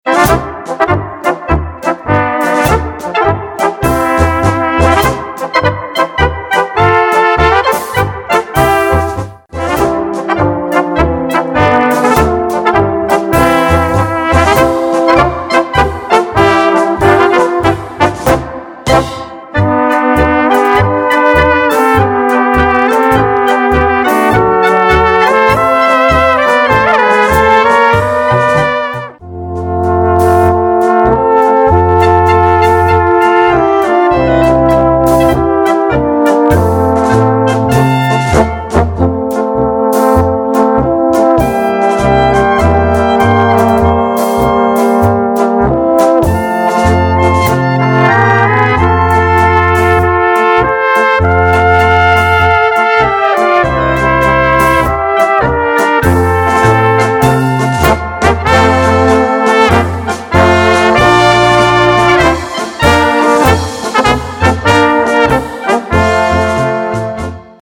Gattung: Polka
Besetzung: Blasorchester
Uralte Polka, fern ab von aktuellen Strömungen und Hektik.